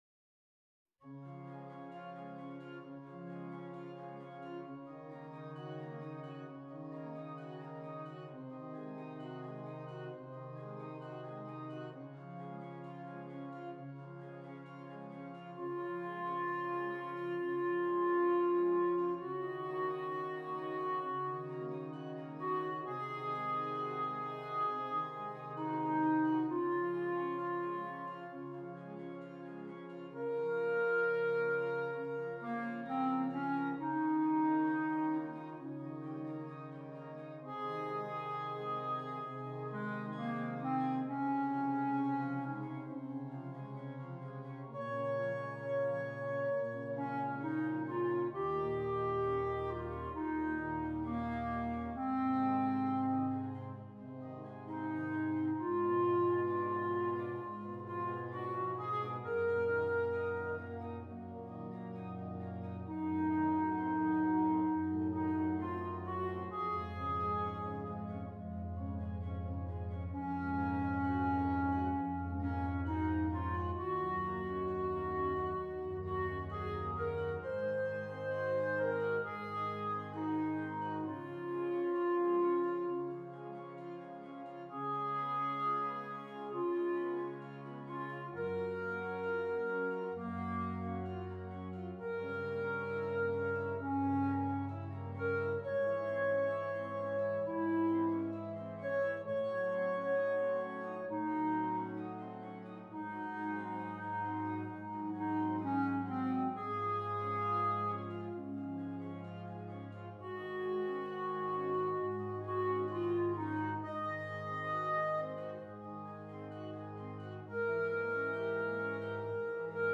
Clarinet and Keyboard